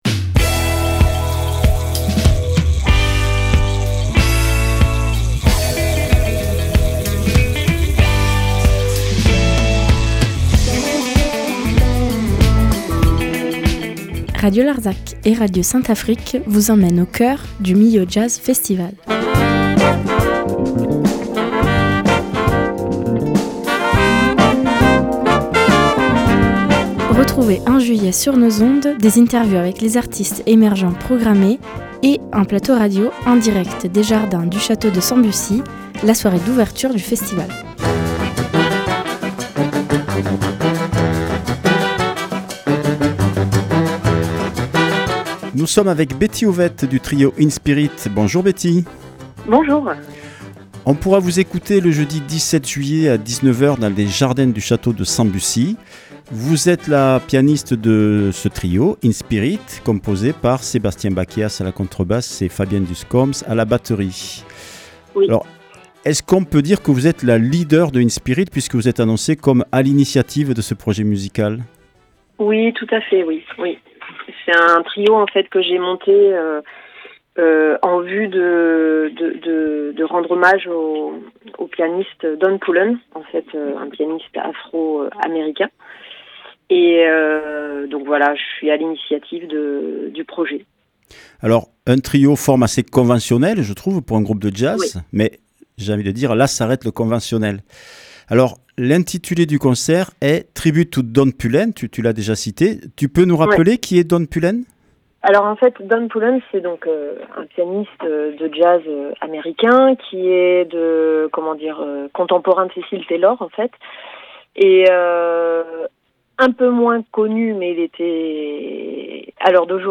Millau Jazz Festival 2025 – Interview